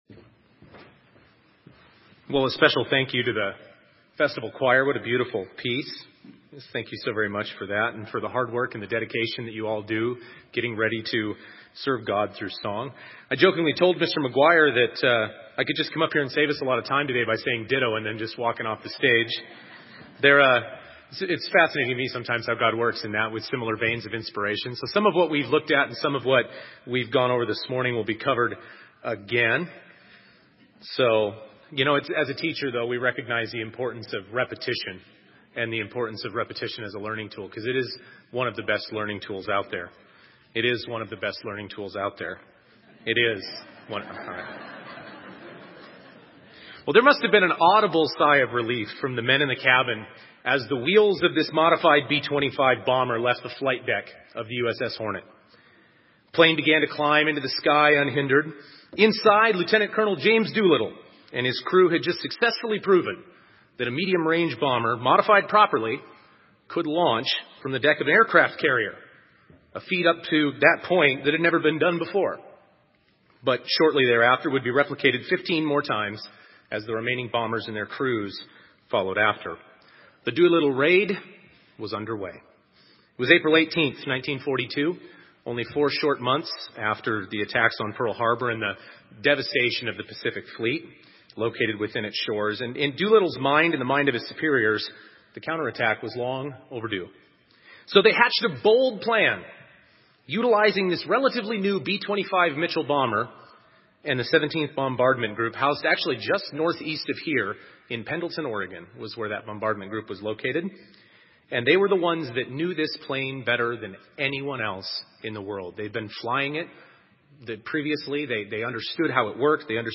This sermon was given at the Bend, Oregon 2015 Feast site.